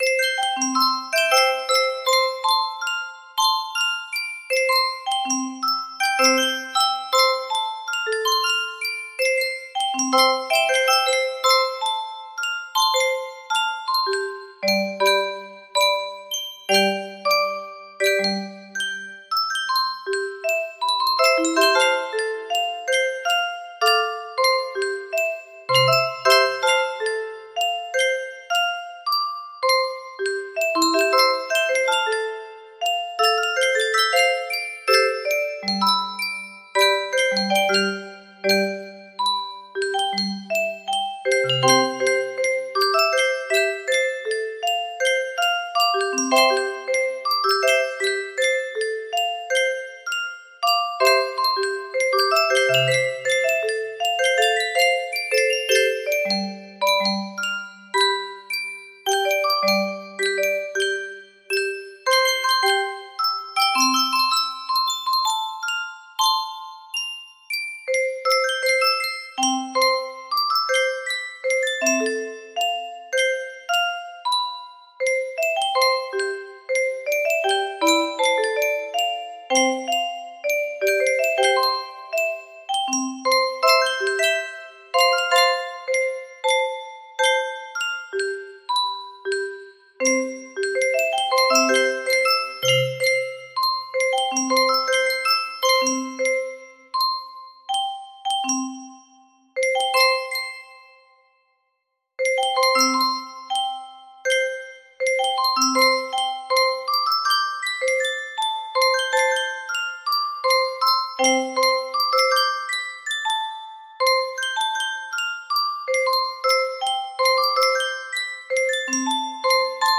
Full range 60
Done, Proper tempo, No Reds.